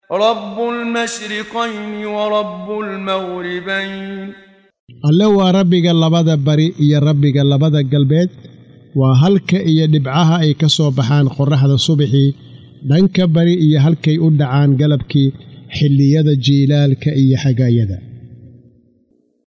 Waa Akhrin Codeed Af Soomaali ah ee Macaanida Suuradda Ar-Raxmaan ( Naxariistaha ) oo u kala Qaybsan Aayado ahaan ayna la Socoto Akhrinta Qaariga Sheekh Muxammad Siddiiq Al-Manshaawi.